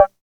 Index of /90_sSampleCDs/Roland - Rhythm Section/DRM_Drum Machine/KIT_CR-78 Kit